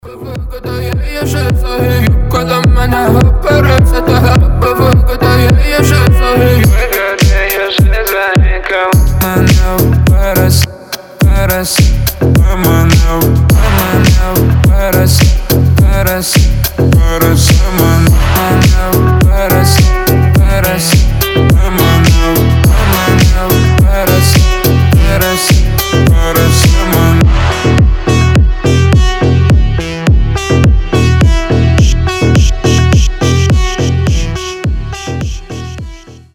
• Качество: 320, Stereo
deep house
басы
качающие
G-House